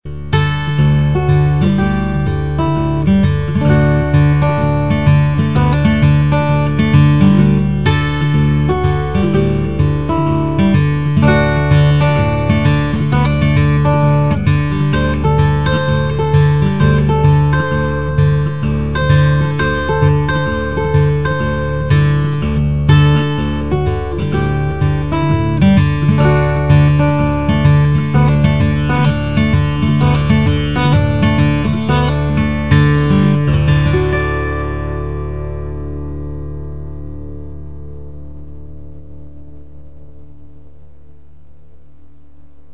I made only one maple/sitka largebody guitar, but it does not sound bright at all. In fact really warm deep basstones.
Here is a simple soundbite (mike into the computer):